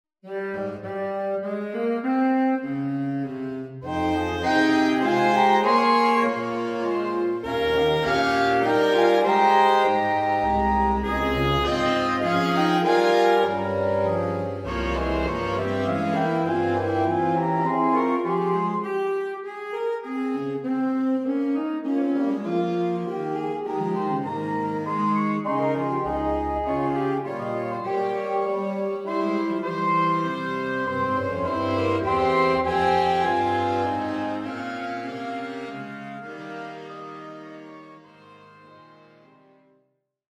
More jazz titles